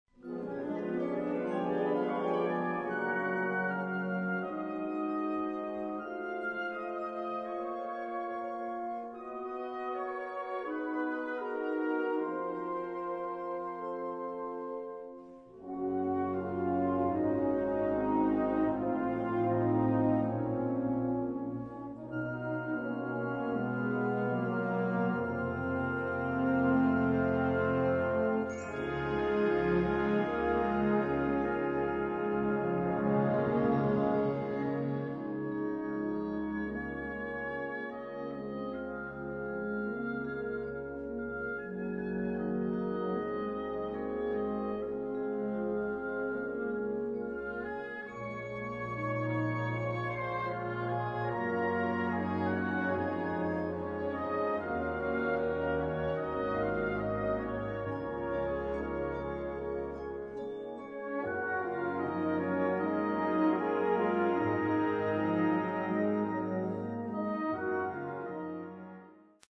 Partitions pour brass band.